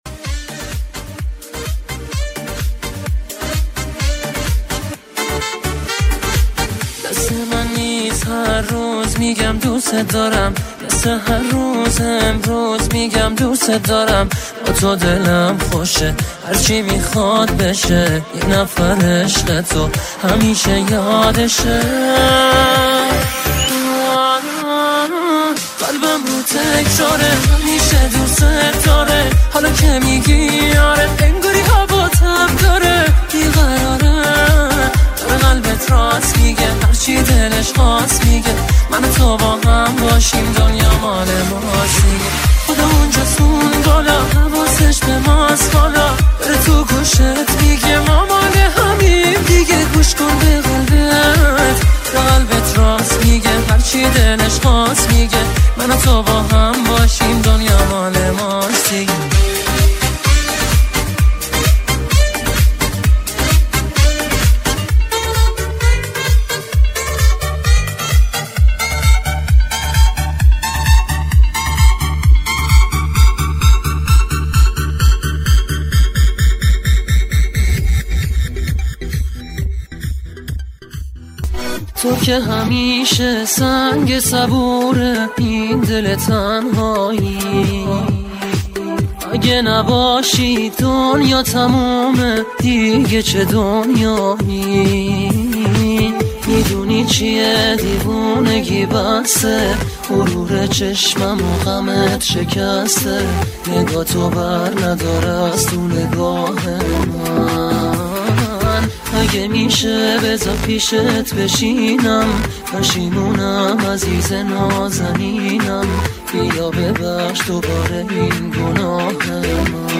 عاشقانه غمگین احساسی